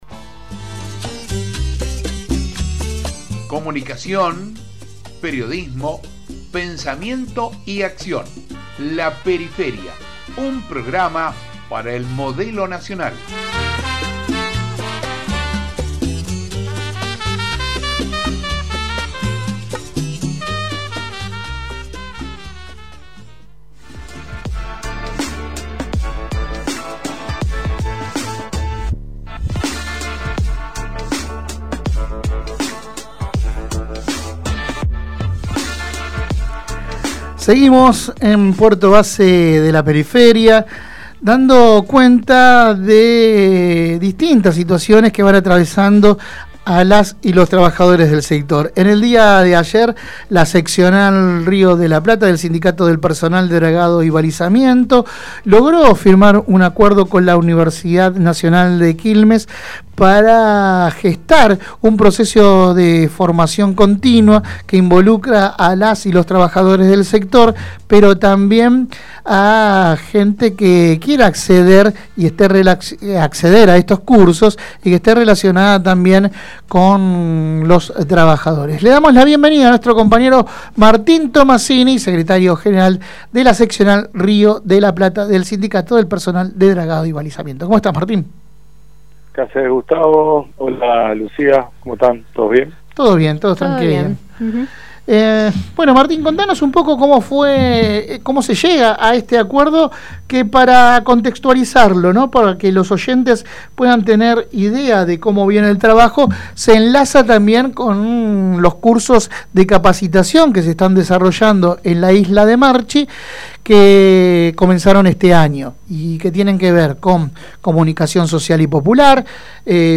Compartimos la entrevista completa: